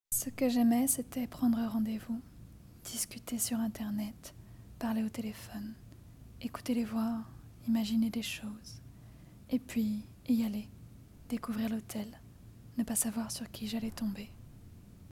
Extrait voix
Voix off